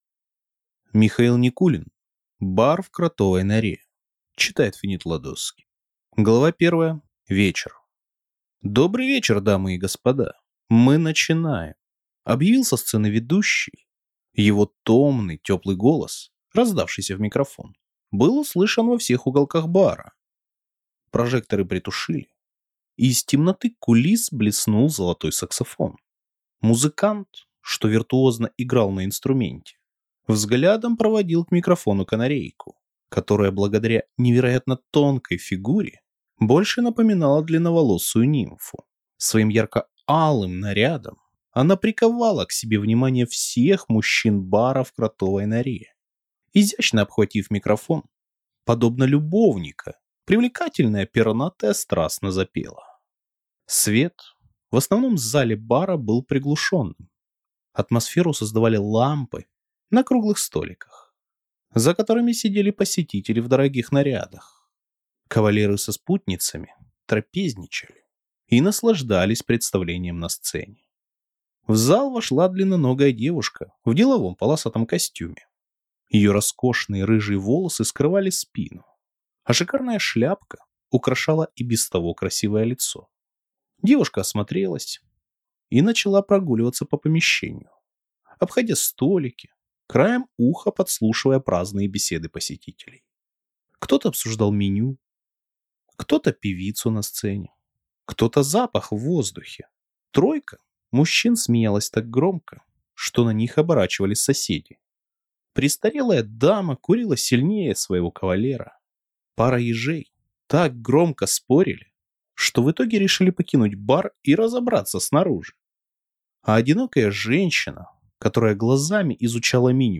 Аудиокнига Бар «В Кротовой Норе» | Библиотека аудиокниг